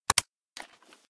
m4a1_switch.ogg